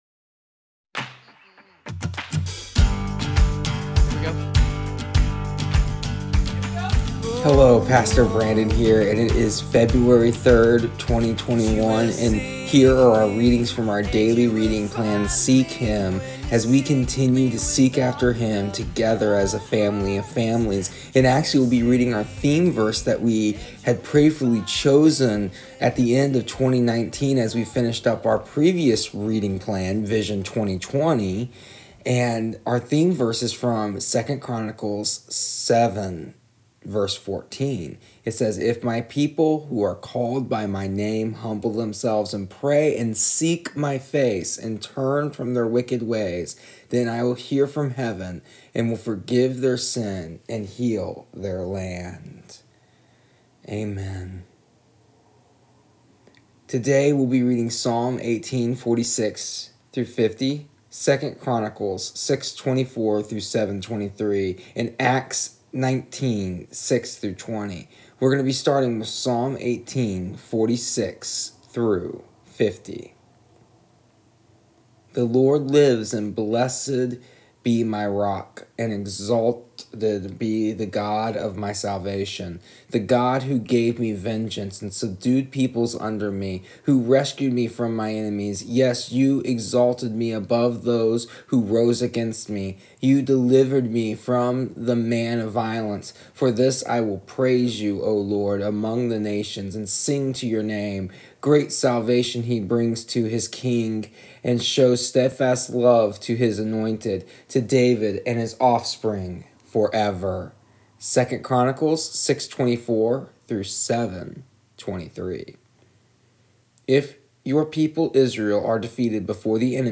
Also, today we are reading out theme verse for our reading plan for the past year and this year 2nd Chronicles 7:14,